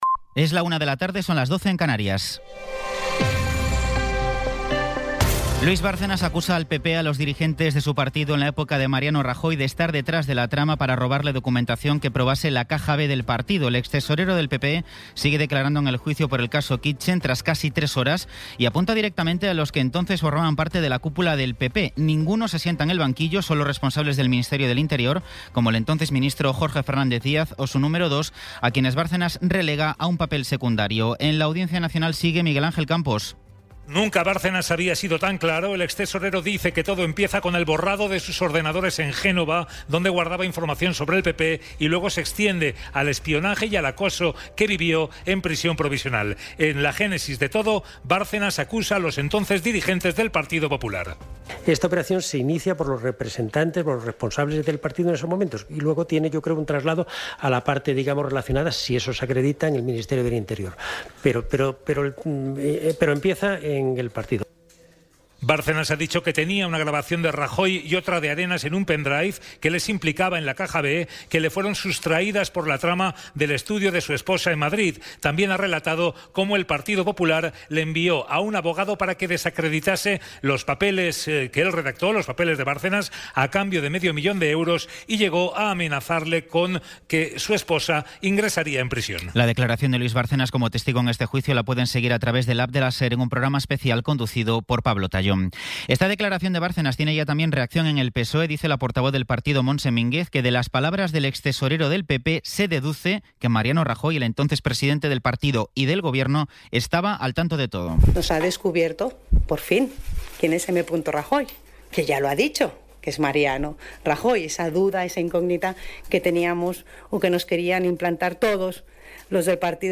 Resumen informativo con las noticias más destacadas del 20 de abril de 2026 a la una de la tarde.